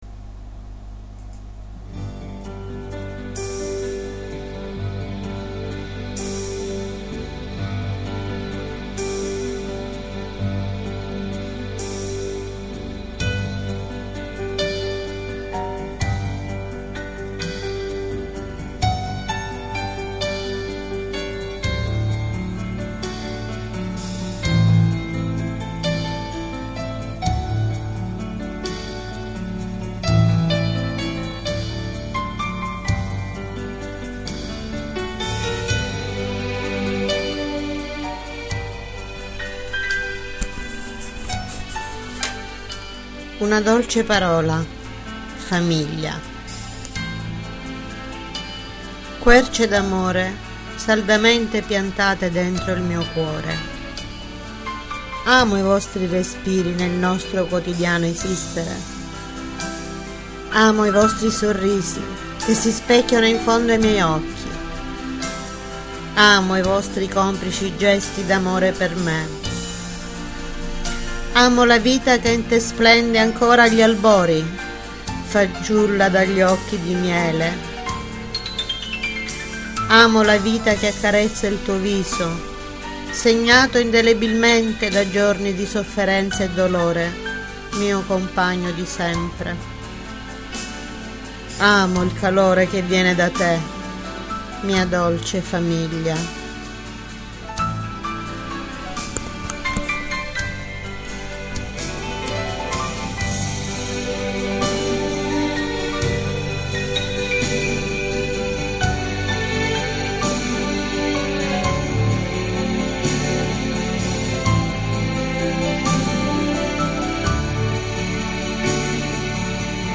POESIE RECITATE - I SENTIERI DEL CUORE - Gabitos